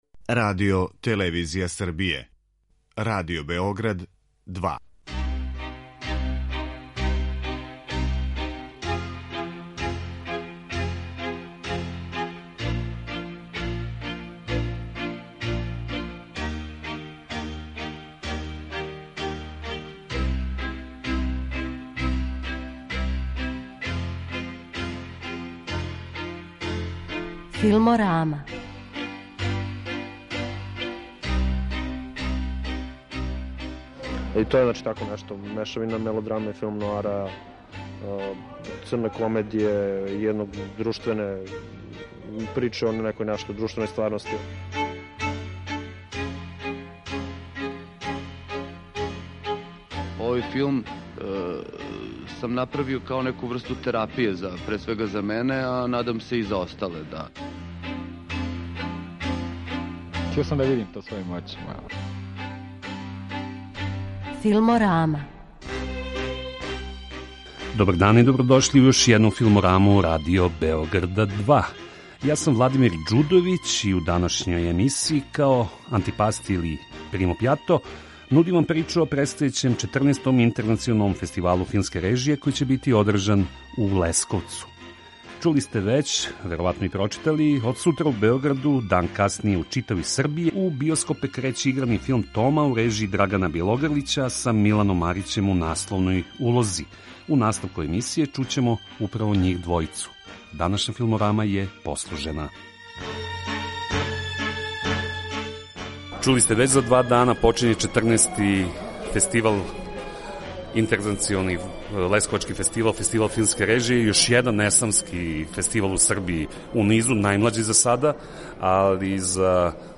Филморама доноси репортажу о том остварењу.